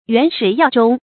原始要終 注音： ㄧㄨㄢˊ ㄕㄧˇ ㄧㄠˋ ㄓㄨㄙ 讀音讀法： 意思解釋： 探求事物發展的起源和結果。